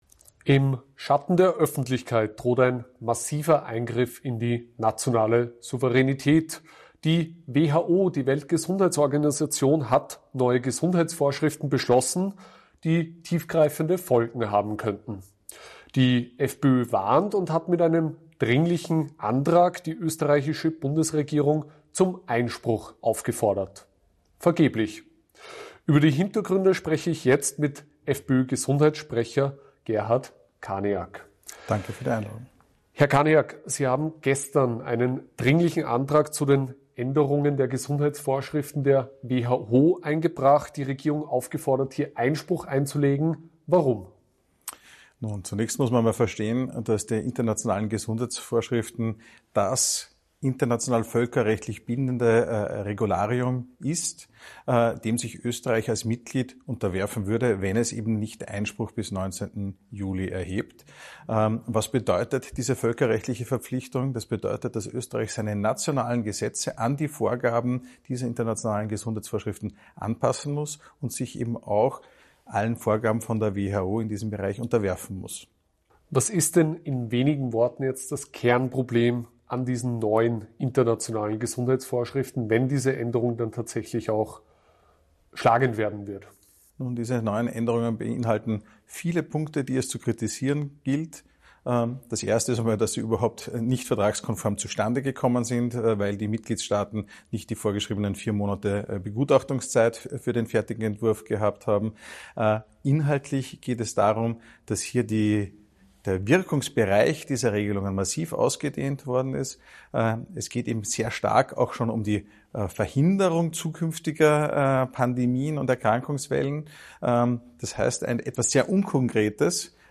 Im AUF1-Interview spricht FPÖ-Gesundheitssprecher Gerhard Kaniak Klartext über die Gefahren der neuen WHO-Gesundheitsvorschriften. Er warnt: Gesundheitsnotstände können künftig zum Dauerzustand erklärt werden.